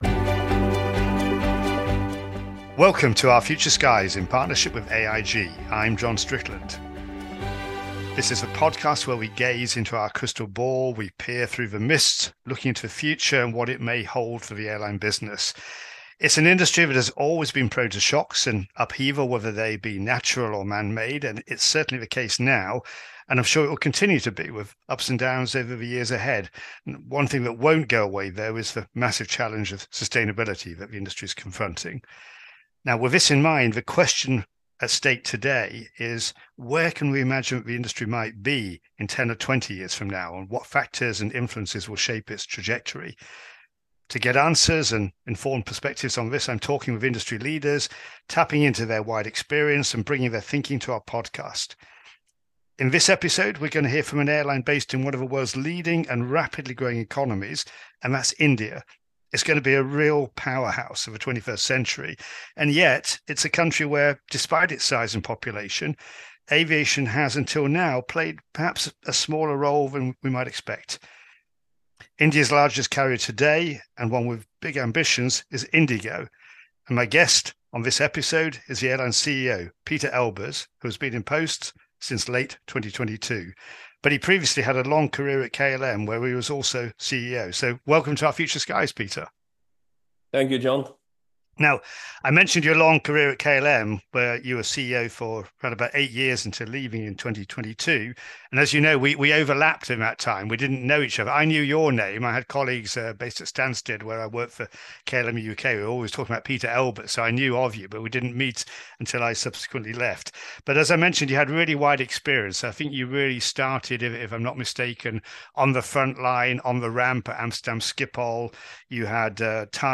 In conversation with Pieter Elbers
Episode contributor: Pieter Elbers – CEO, IndiGo